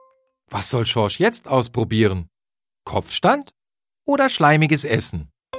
interaktive Hörabenteuer zum Mitbestimmen
…sehr professionell produziert  (Forum Logopädie)
…professionelle Sprechweise des Vorlesers